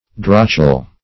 \Drotch"el\